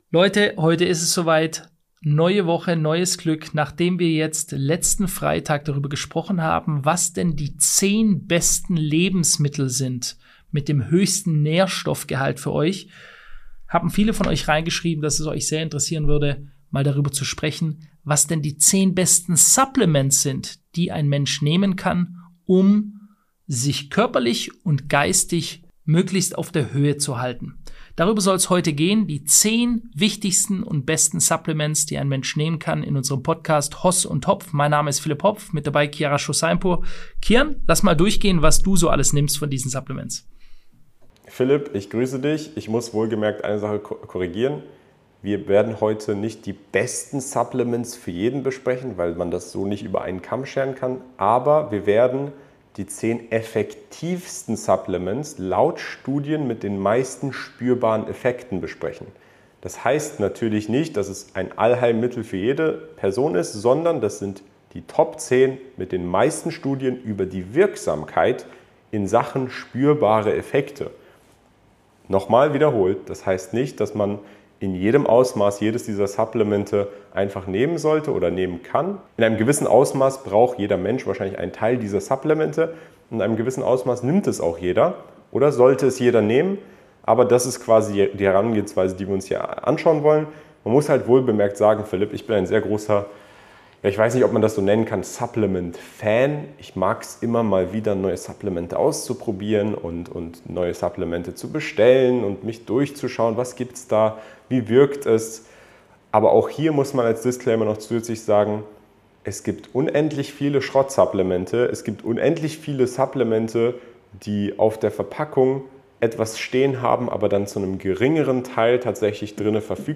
Eine facettenreiche Diskussion über Supplementforschung, Studienmethodik und die Bewertung von Wirksamkeitsversprechen.